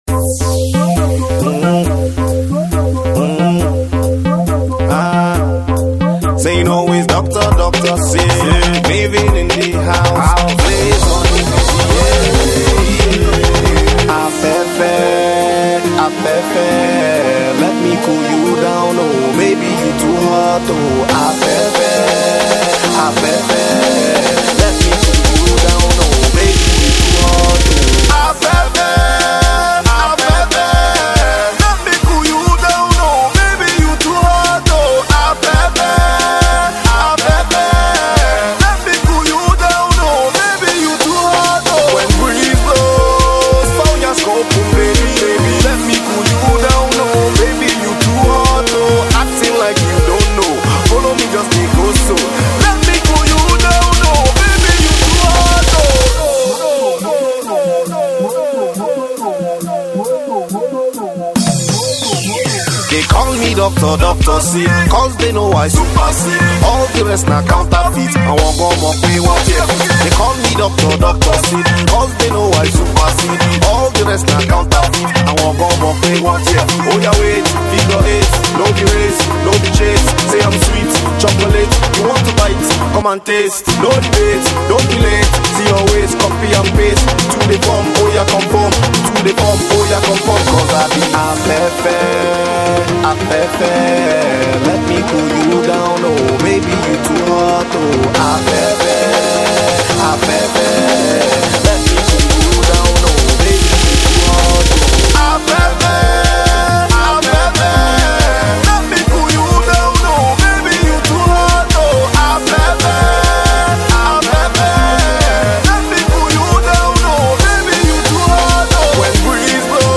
a banger
poised for club domination.